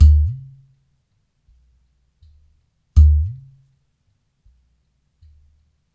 80 -UDUS 09.wav